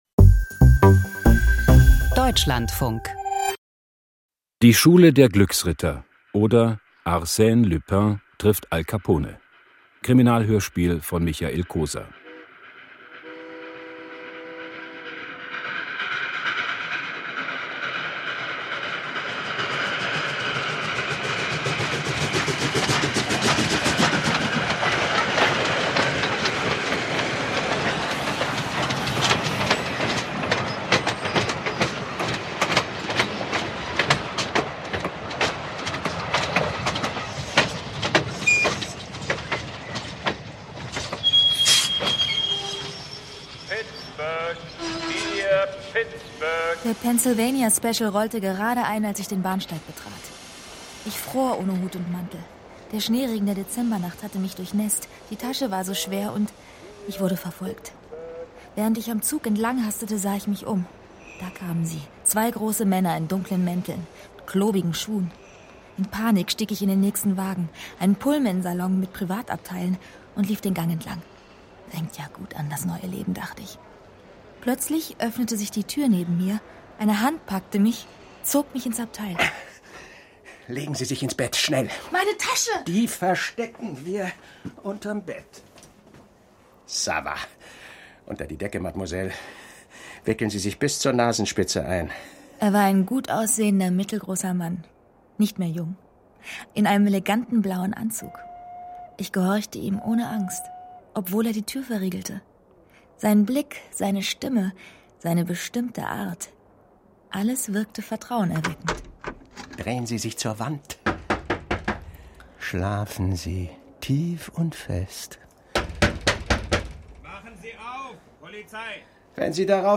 Krimi-Hörspiel